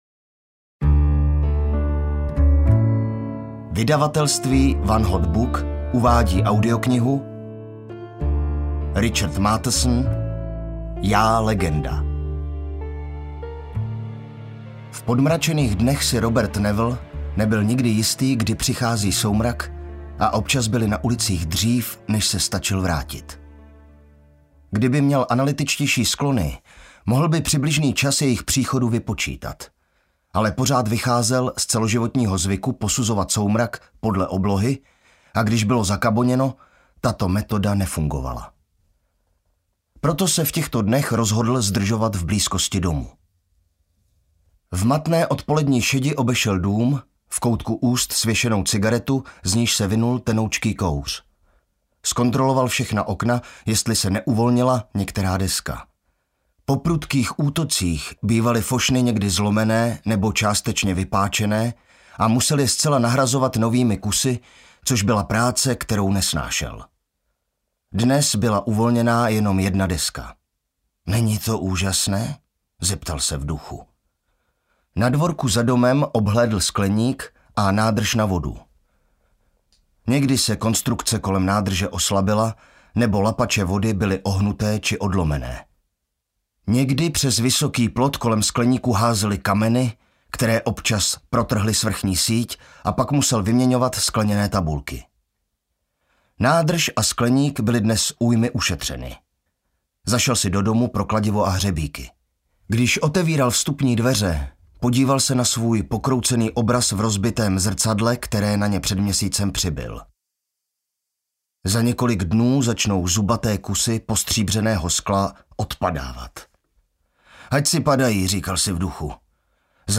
Audiobook
Read: Jan Dolanský